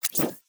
Cybernetic Technology Affirmation 7.wav